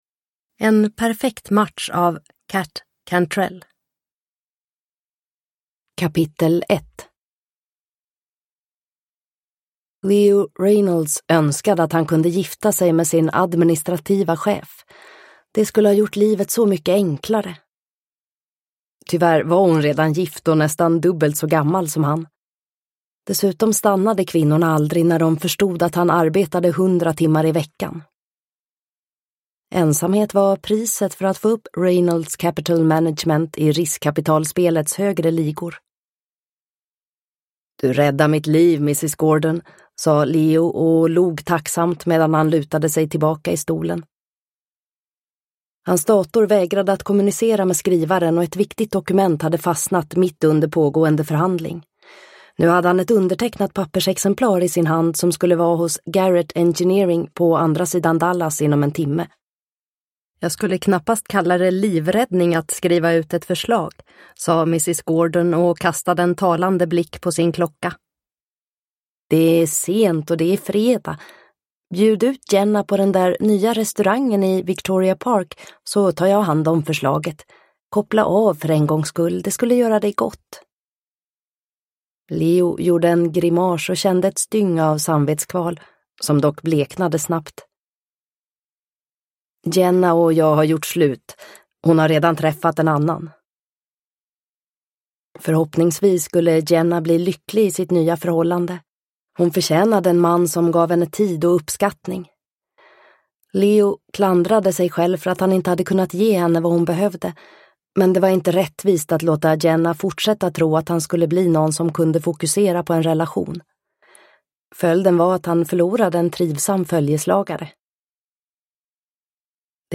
En perfekt match – Ljudbok – Laddas ner